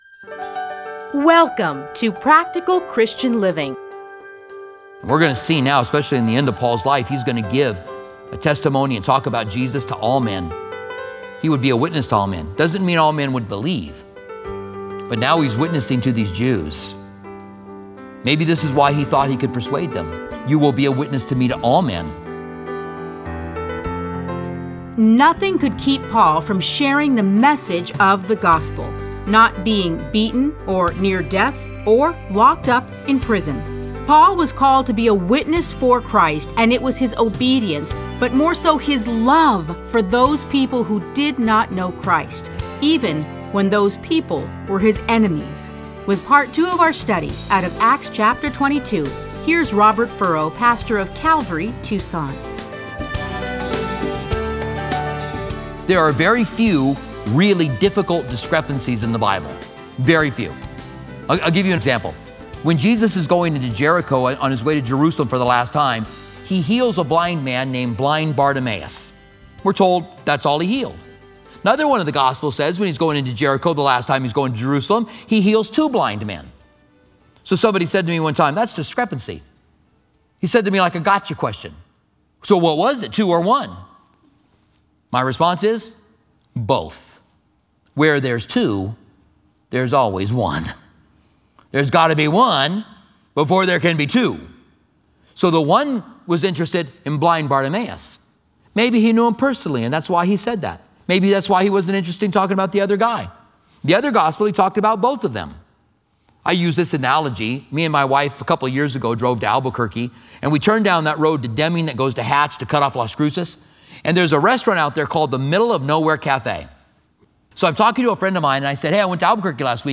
Listen to a teaching from Acts 22.